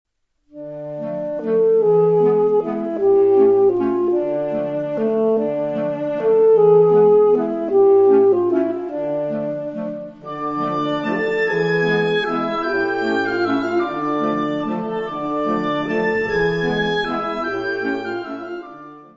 Instrumentation Ha (orchestre d'harmonie)